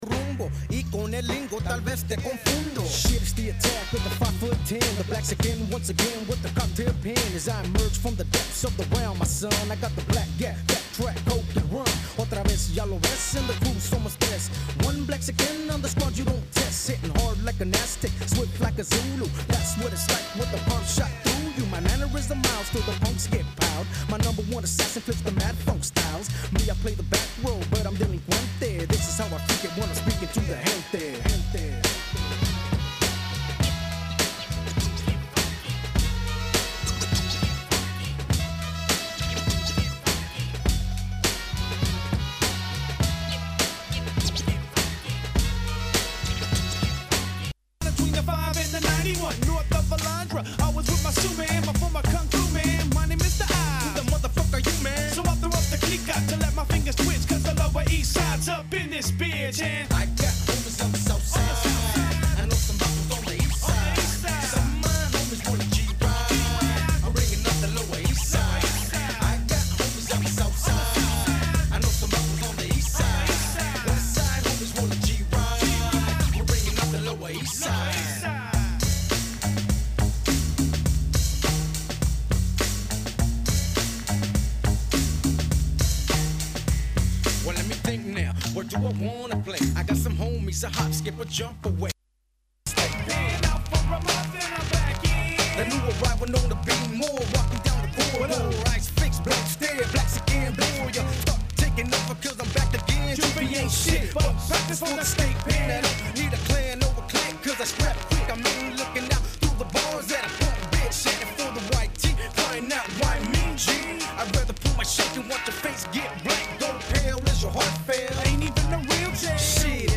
⌂ > Vinyly > Hiphop-Breakbeat >